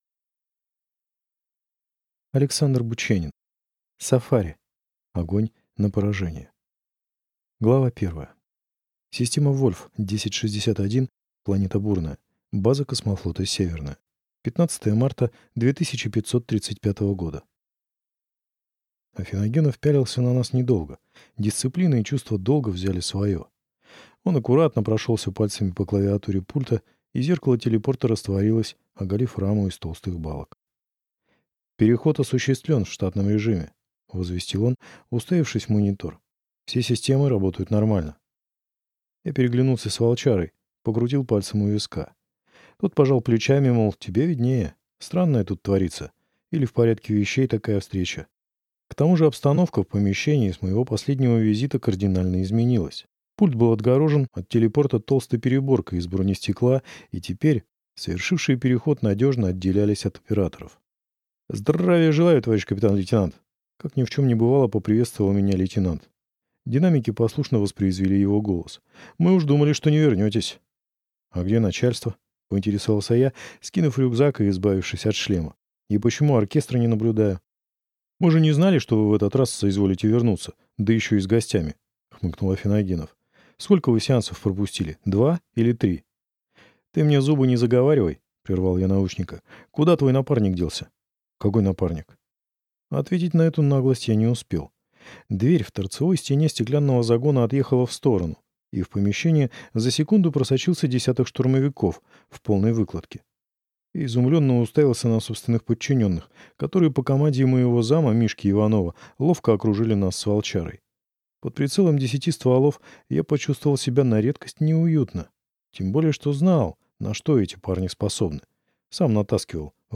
Аудиокнига Сафари. Огонь на поражение | Библиотека аудиокниг